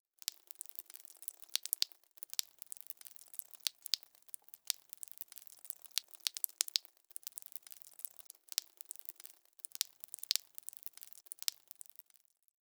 Blood DSS01_35.wav